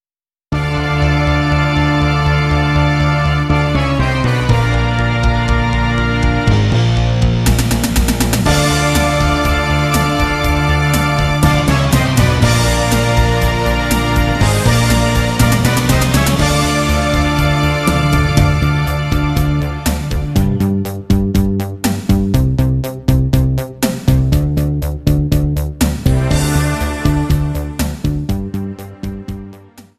Backing track Karaoke
Musical/Film/TV, 1980s